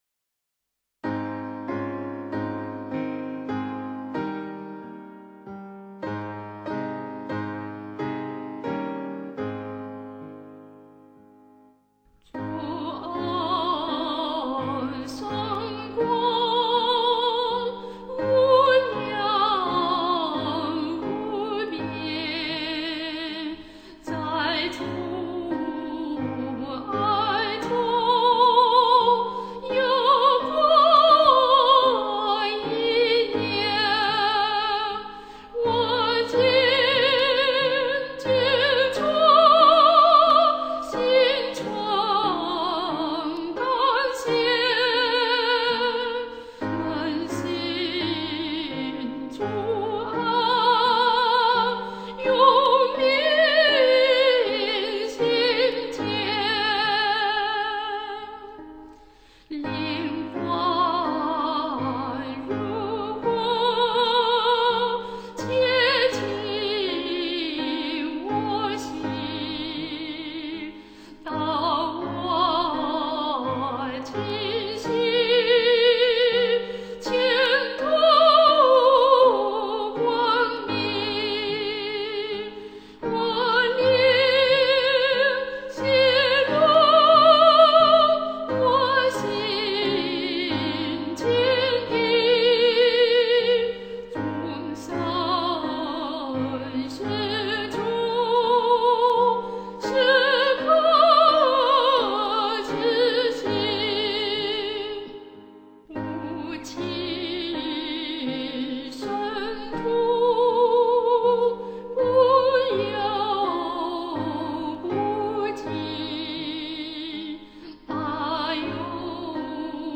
示唱